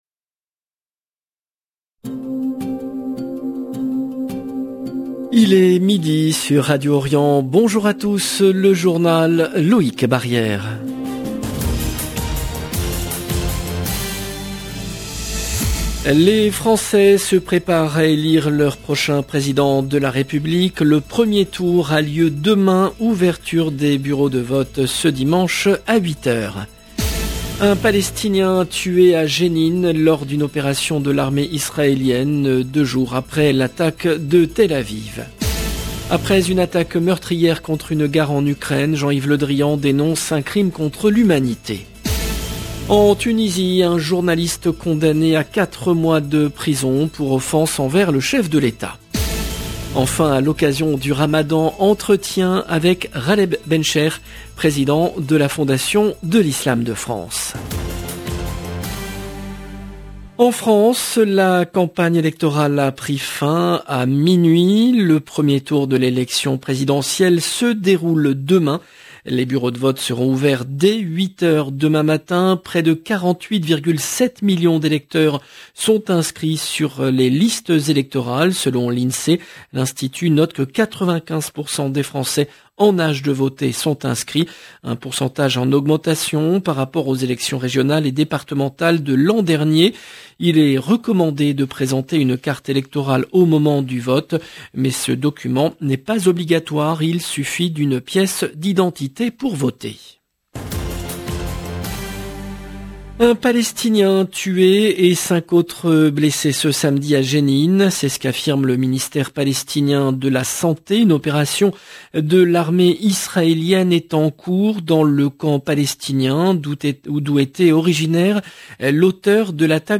LE JOURNAL DE MIDI EN LANGUE FRANCAISE DU 9/04/22
Enfin à l’occasion du ramadan, entretien avec Ghaleb Bencheikh, président de la Fondation de l’Islam de France.